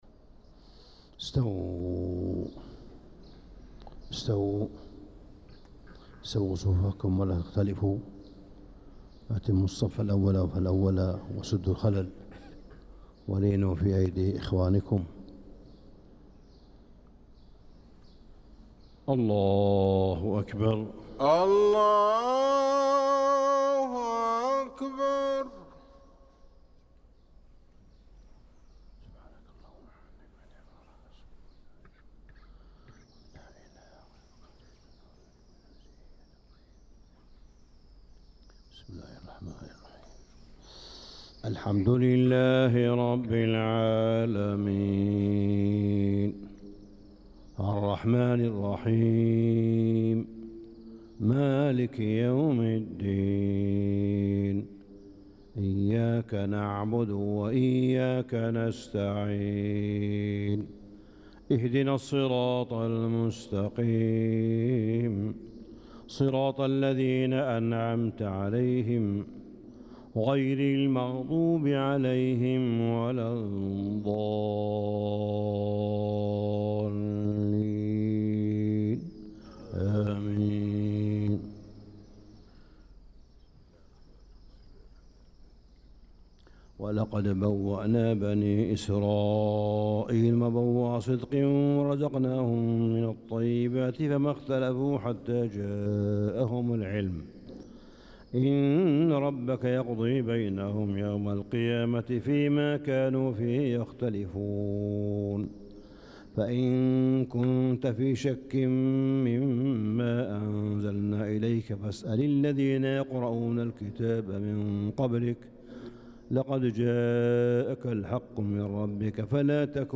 صلاة الفجر للقارئ صالح بن حميد 27 ذو القعدة 1445 هـ
تِلَاوَات الْحَرَمَيْن .